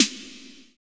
Index of /90_sSampleCDs/Roland - Rhythm Section/DRM_Drum Machine/DRM_Cheese menus